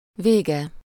Ääntäminen
IPA: /fɛ̃/